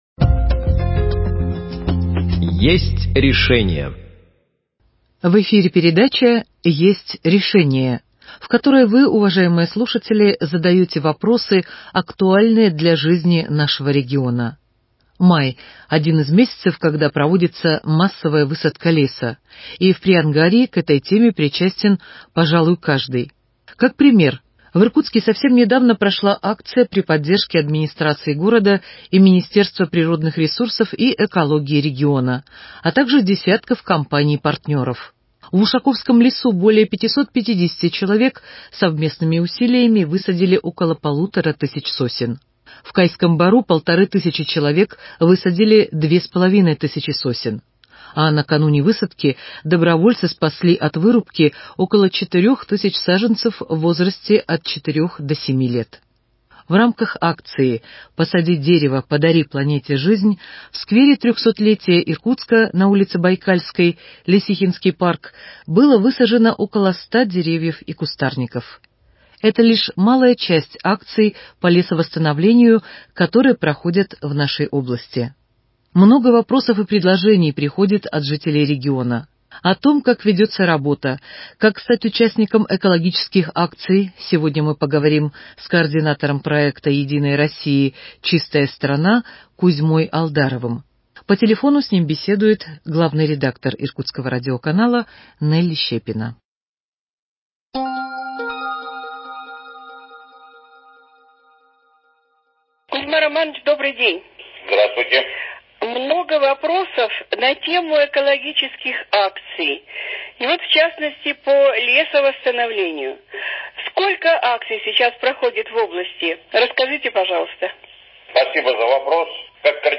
Беседует с ним по телефону